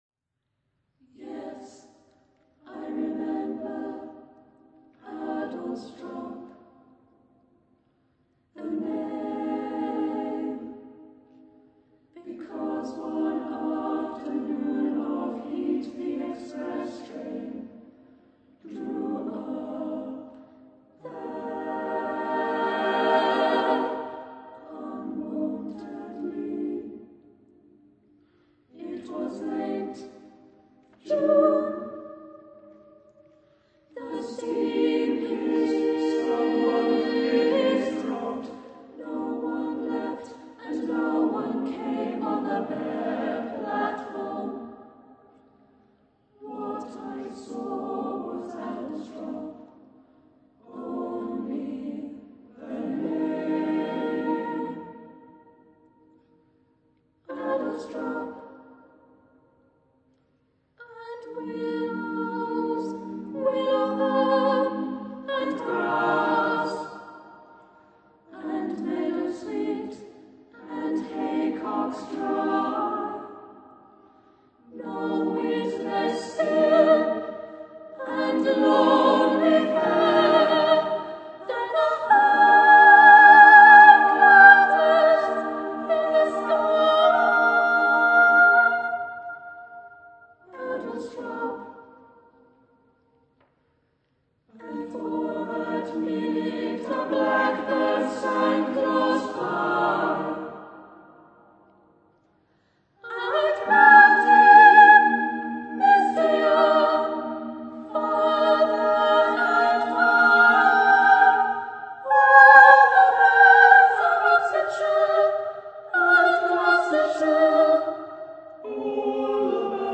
for unaccompanied mixed voice choir
unaccompanied mixed voice choir (SATB with divisi)
Choir - Mixed voices (SATB)